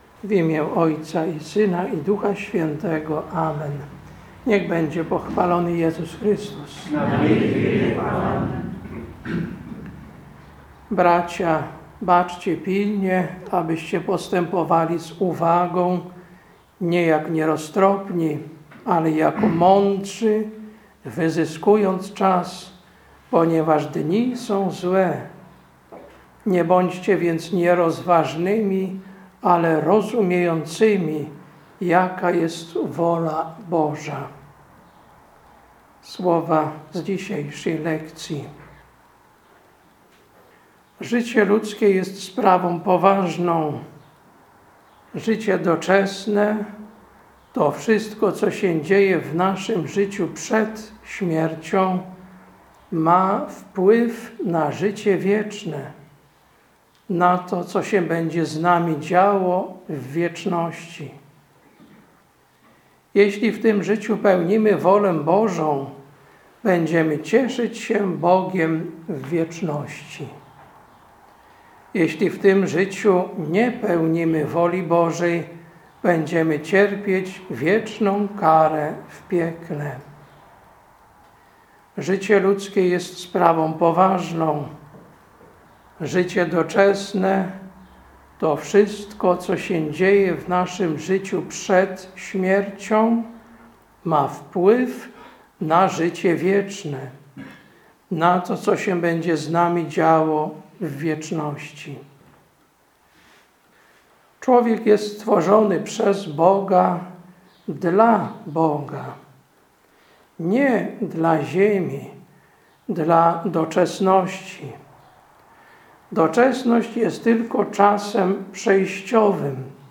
Kazanie na XX Niedzielę po Zesłaniu Ducha Świętego, 23.10.2022
Kazanie na XX Niedzielę po Zesłaniu Ducha Świętego, 23.10.2022. Msza Święta w rycie rzymskim.